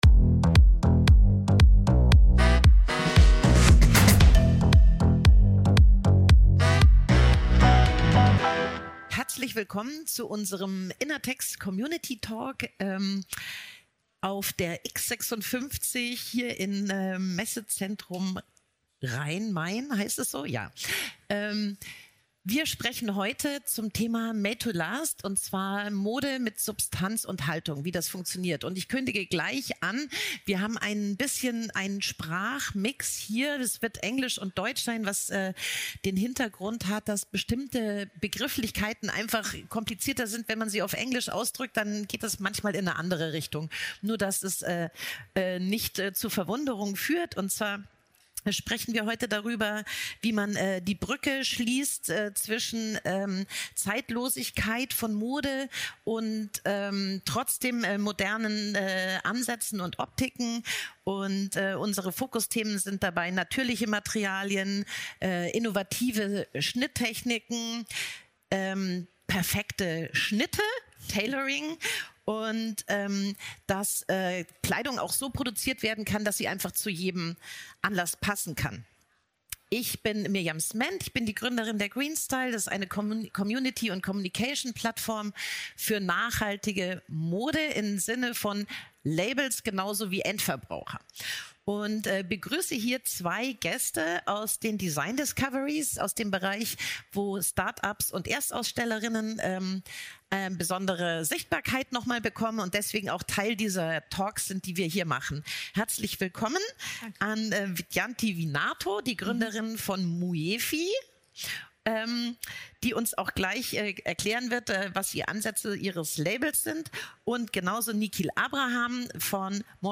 Kleidung ist dann über die Saisons hinweg relevant, wenn sie es schafft, den vermeintlichen Widerspruch zwischen Modernität und Zeitlosigkeit zu vereinen. Im Fokus dieses Talks stehen: Naturmaterialien, spannende Schnitttechniken und Designs, die sich an Situationen und Körper anpassen....